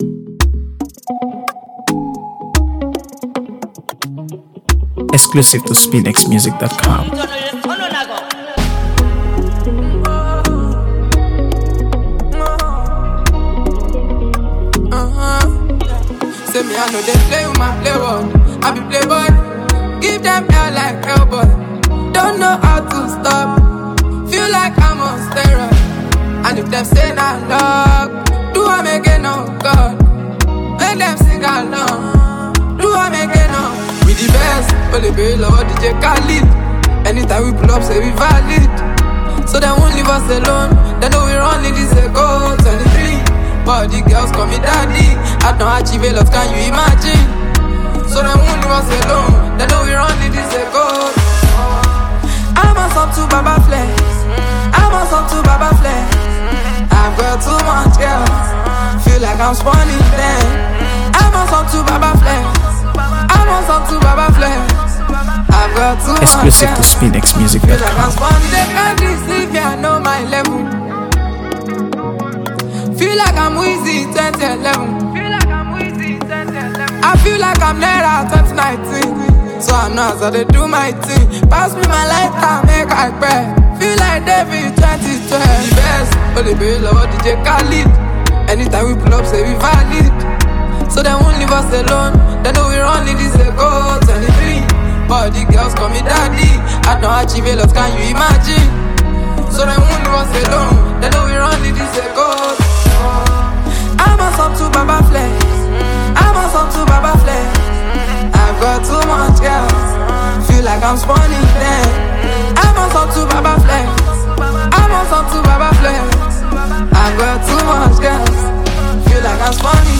AfroBeats | AfroBeats songs
is packed with bounce, rhythm, and undeniable energy.